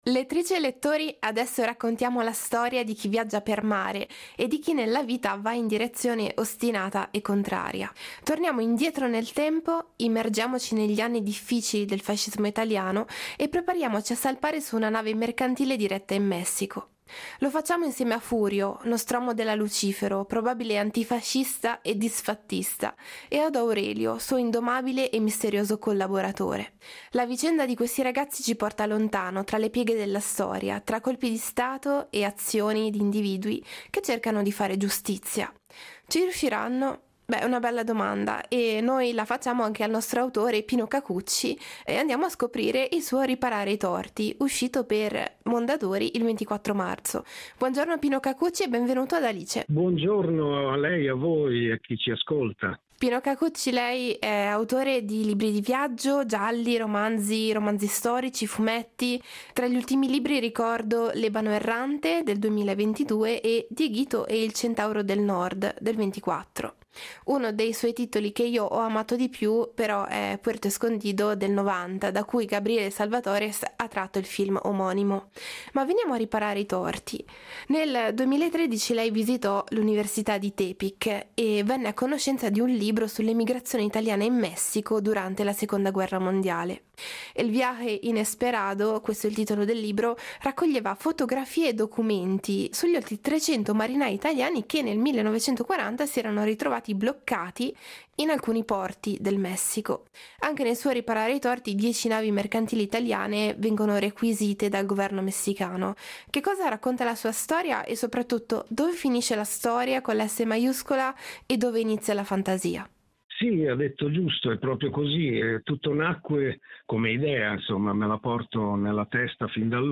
Intervista a Pino Cacucci